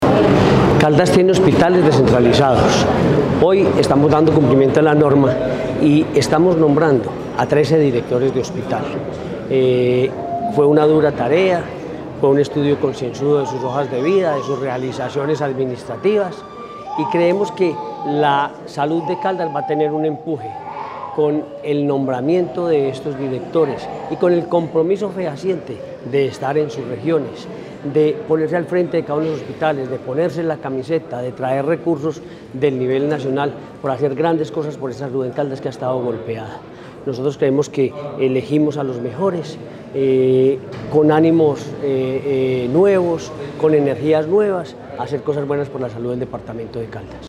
Gobernador de Caldas, Henrry Gutiérrez.